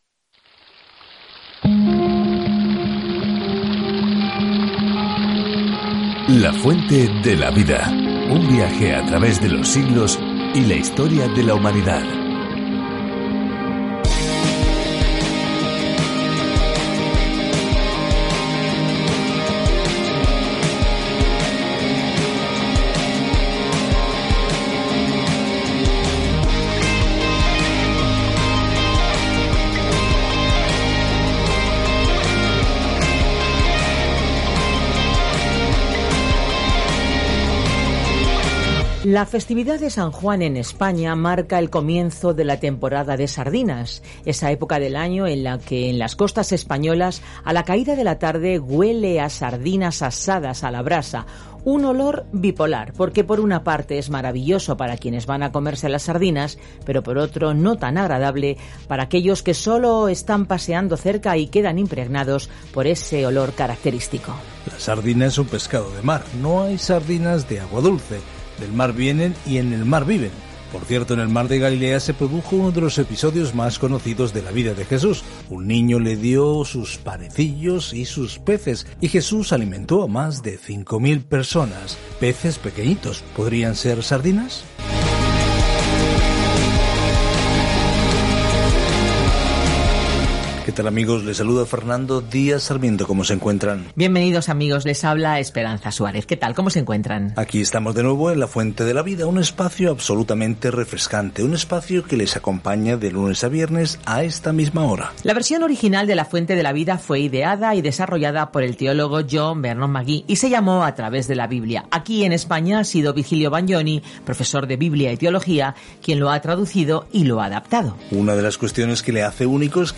Escritura HABACUC 1:1 Iniciar plan Día 2 Acerca de este Plan Habacuc pregunta con un gran “¿por qué, Dios?” Al comienzo de una serie de preguntas y respuestas con dios sobre cómo trabaja en un mundo malvado. Viaja diariamente a través de Habacuc mientras escuchas el estudio en audio y lees versículos seleccionados de la palabra de Dios.